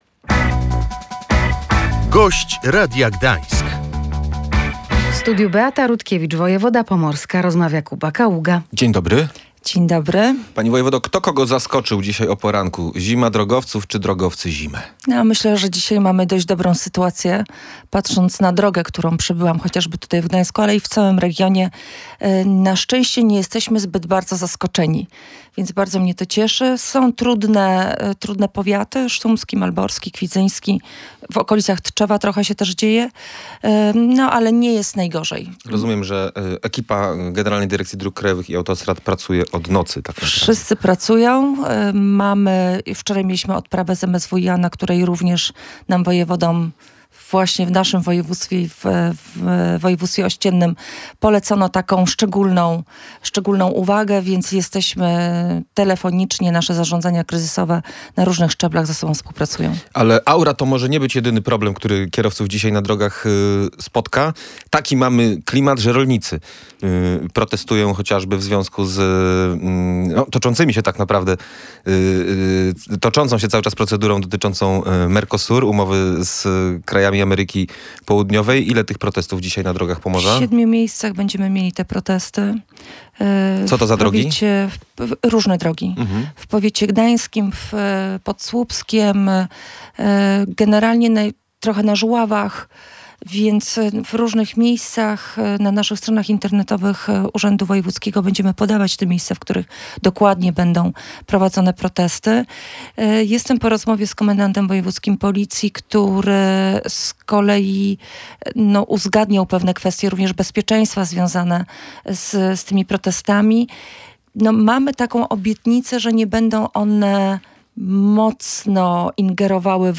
Rząd nie przyjął projektu ustawy metropolitalnej dla Pomorza. Wojewoda pomorska Beata Rutkiewicz przyznała w Radiu Gdańsk, że nie jest już w tej sprawie optymistką i nie wiadomo, kiedy projekt zostanie zaakceptowany.
W rozmowie poruszony został także program ochrony ludności i obrony cywilnej. Wojewoda pomorska przyniosła do studia poradnik bezpieczeństwa.